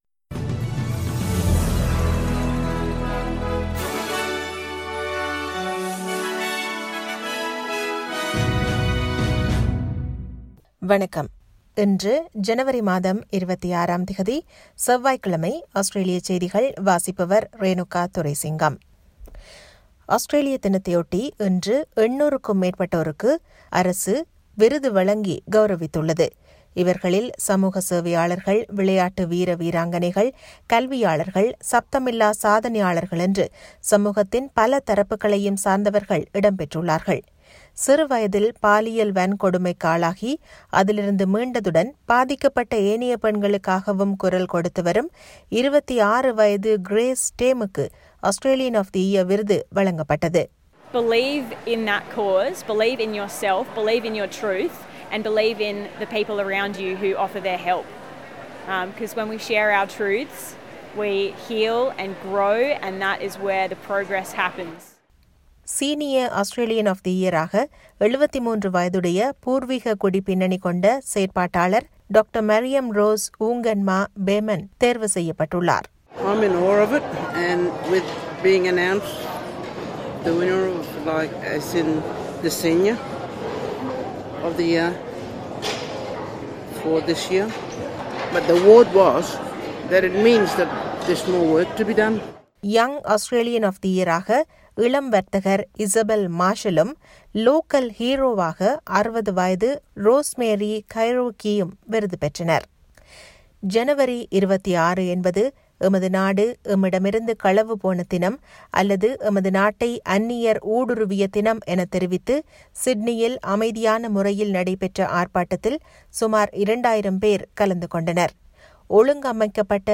Australian news bulletin for Tuesday 26 January 2021.